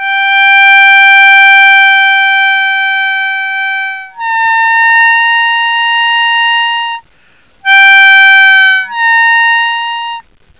When done properly you will hear the note begin to bend down then pop up 3 semi tones. (If you are on a C harp the blow 6 is a G note the overblow is a Bb.)
overblow6.wav